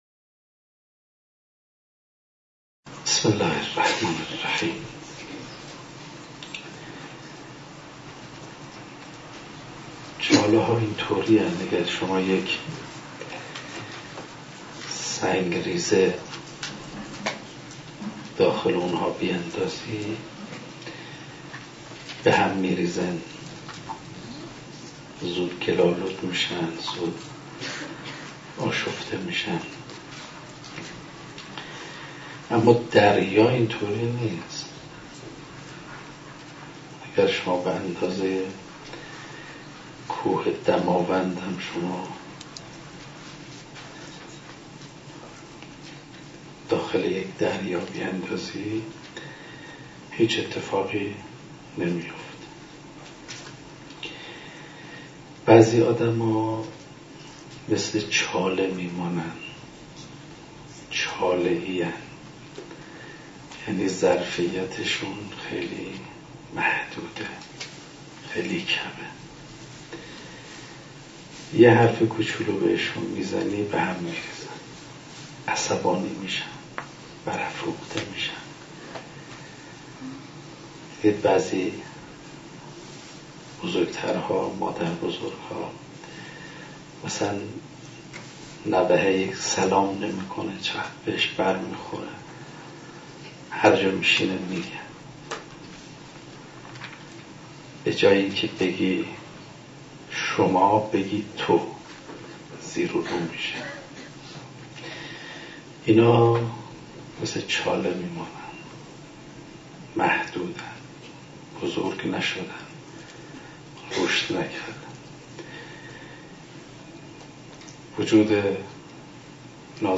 064 - تلاوت قرآن کریم